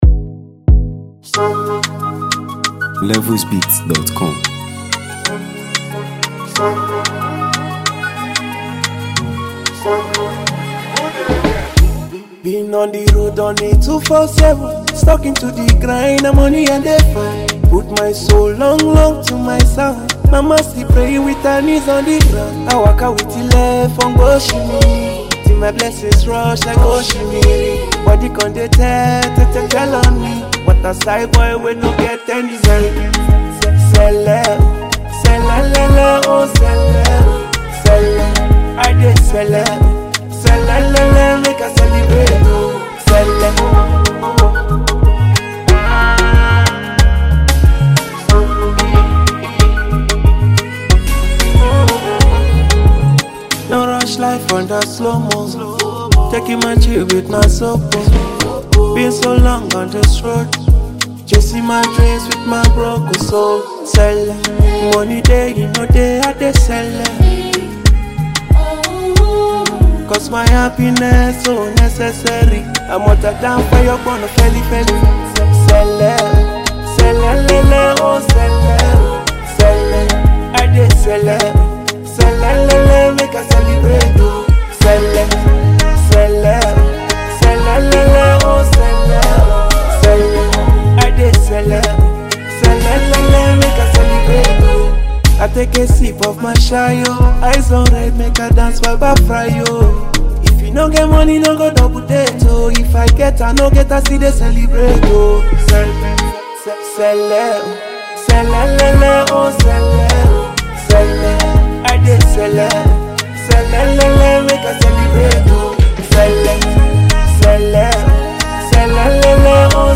is a vibrant and energetic track